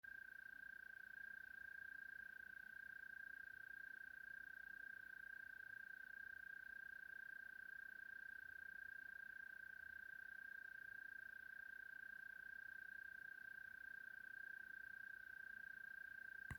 Zemesvēzis, Gryllotalpa gryllotalpa
Administratīvā teritorijaLīvānu novads
Notes/Dzied